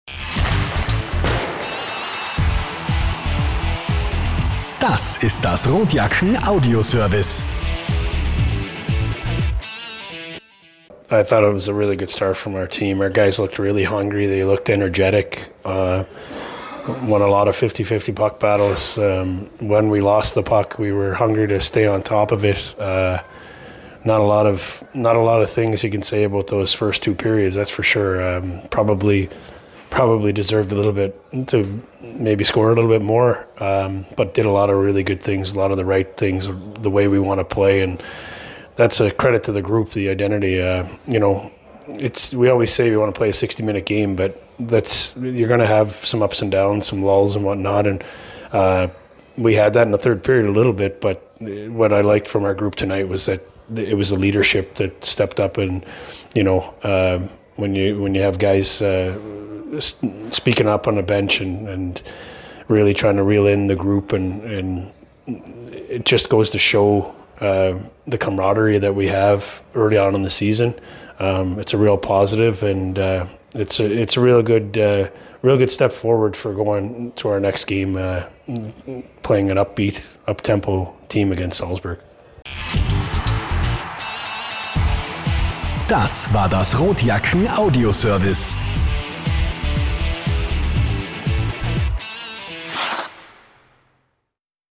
Post Game-Kommentar